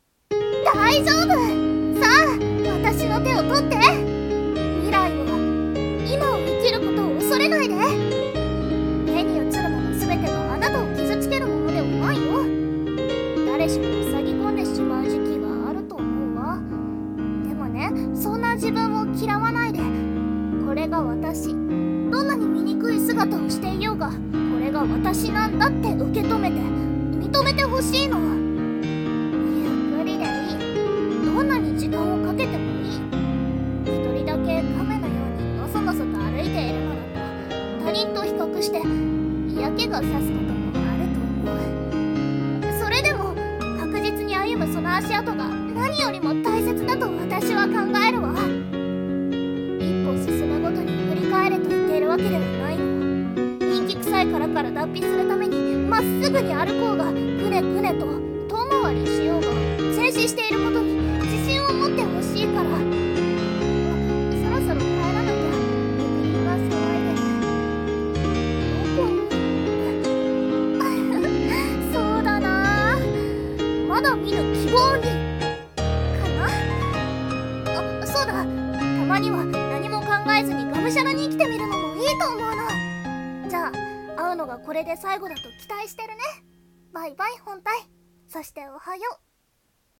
【一人声劇】【朗読】前略、○○。